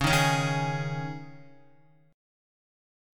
Dbsus2 chord